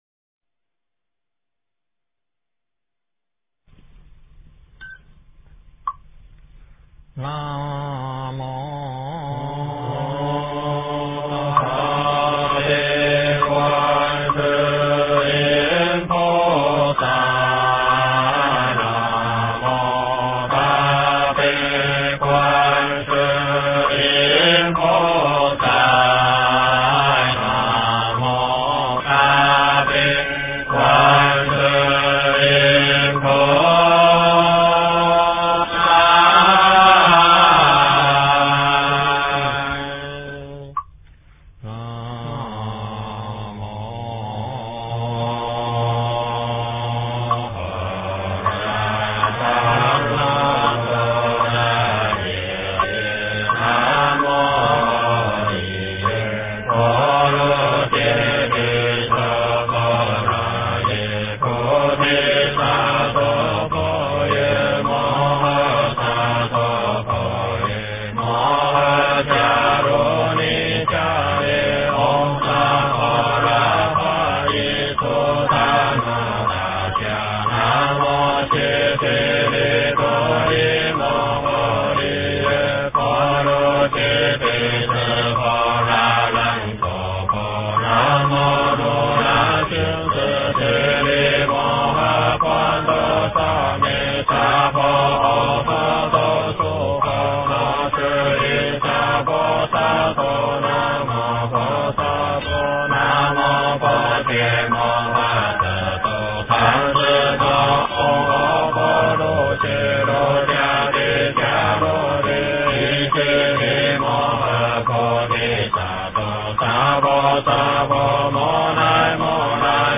经忏
佛音
佛教音乐